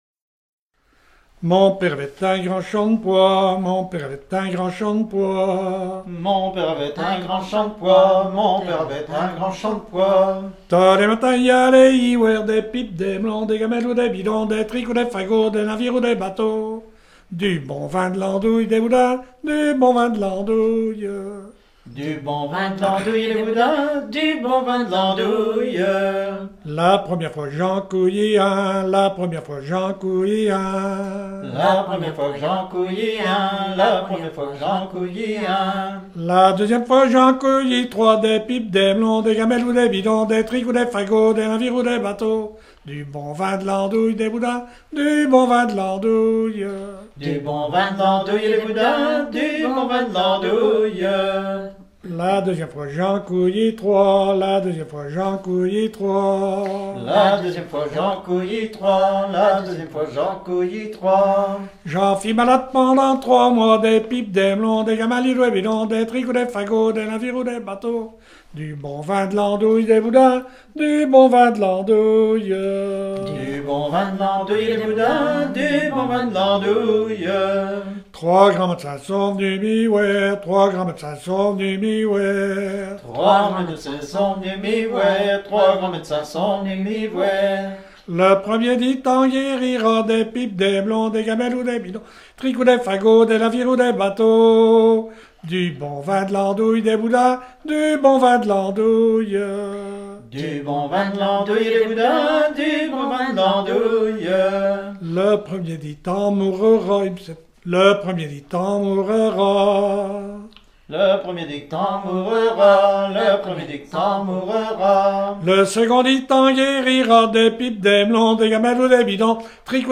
Langue Patois local
Genre laisse